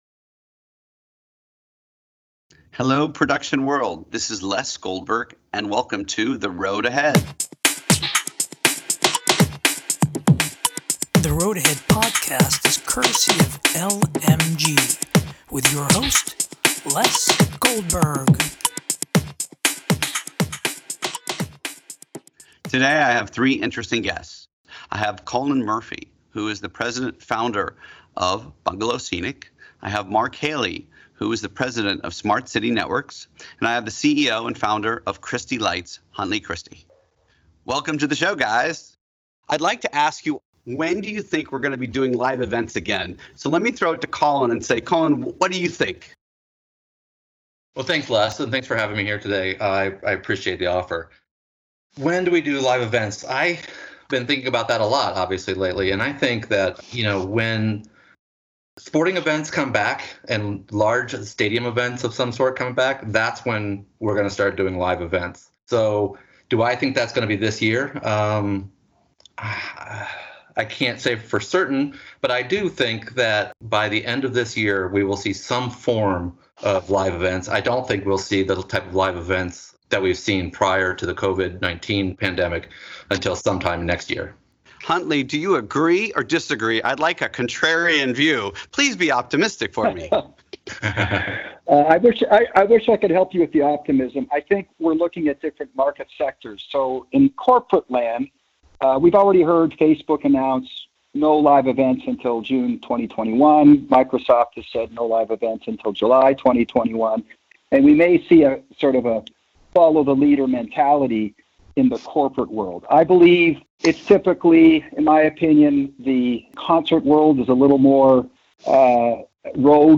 A discussion of the current state of the live events industry.